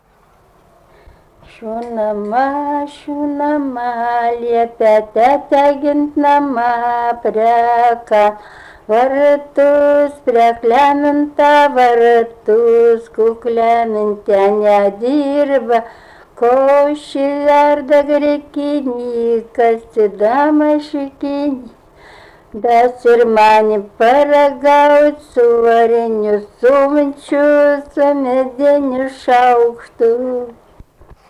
smulkieji žanrai
Jonava
vokalinis